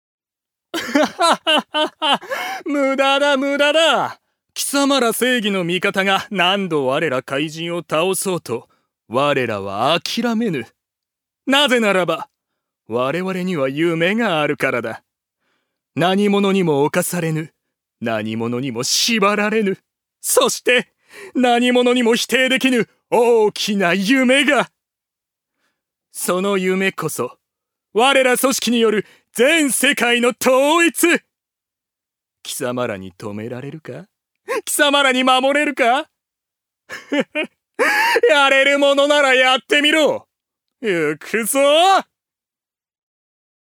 所属：男性タレント
4. セリフ４